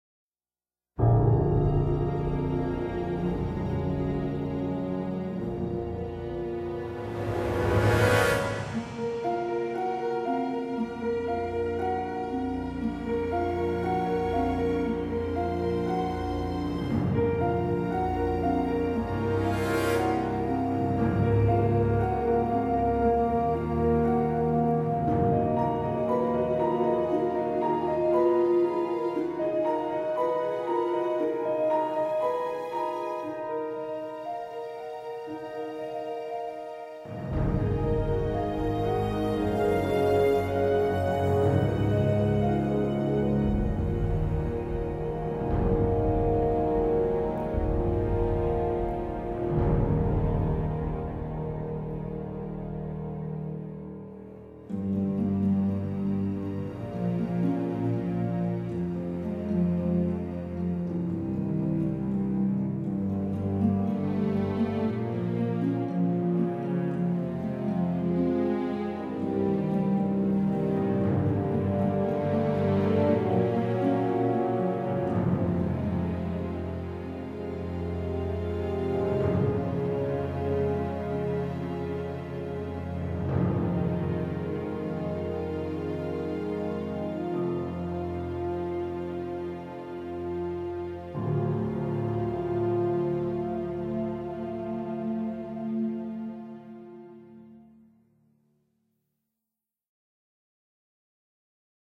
Musique diffusée: Pendant le résumé des épisodes précédents.